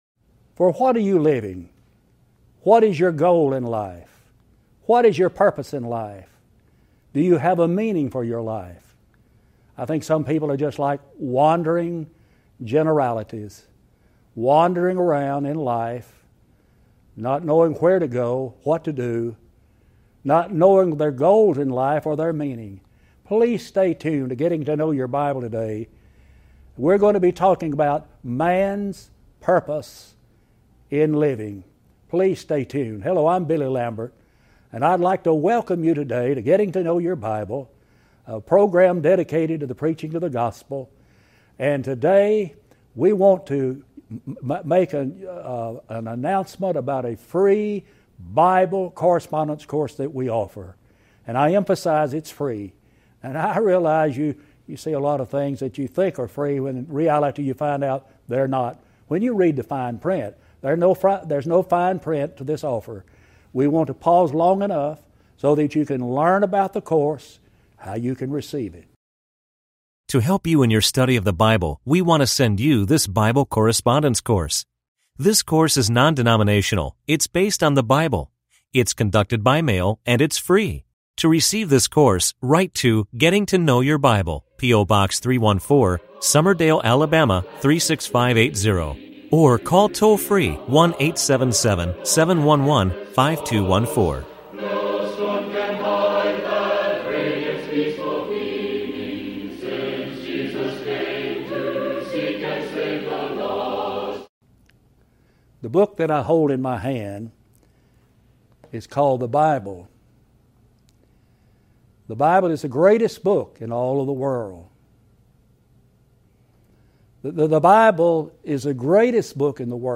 Talk Show Episode, Audio Podcast, Getting To Know Your Bible and Ep1315, Mans Purpose In Life on , show guests , about Mans Purpose In Life, categorized as History,Love & Relationships,Philosophy,Psychology,Religion,Christianity,Inspirational,Motivational,Society and Culture